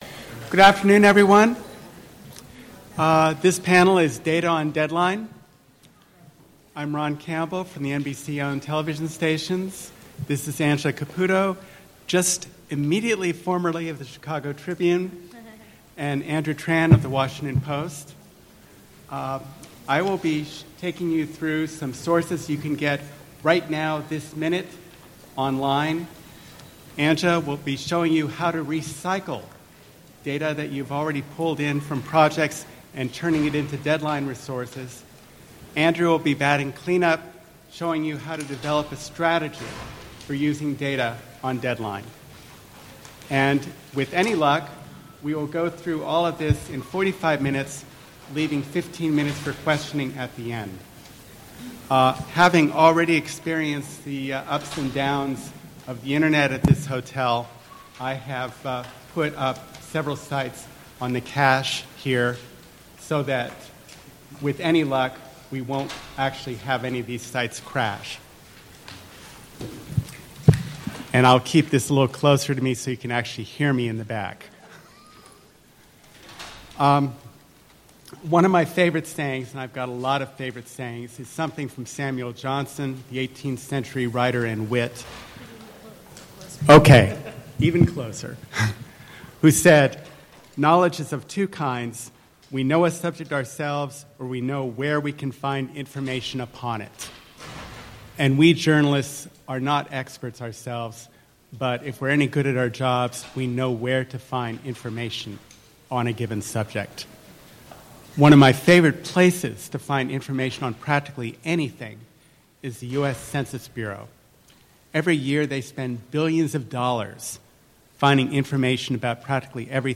Recommended: IRE conference audio “ Data on Deadline ”, with slides .